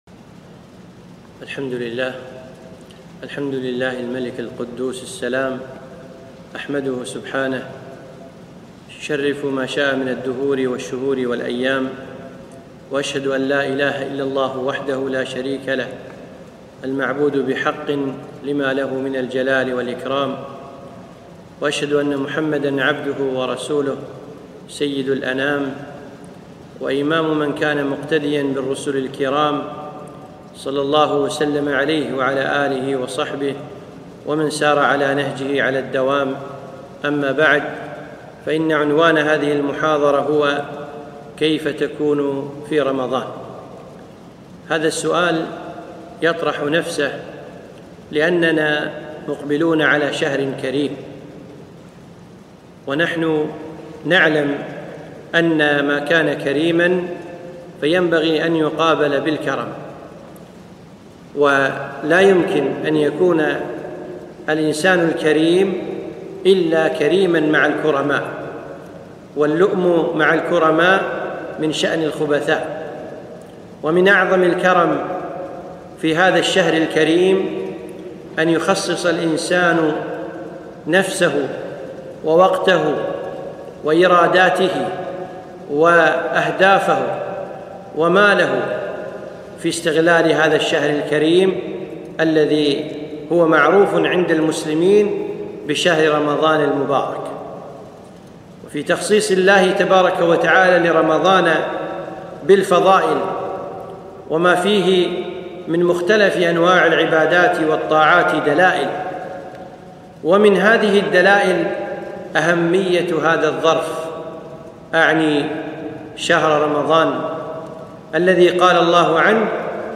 محاضرة - كيف تكون في رمضان؟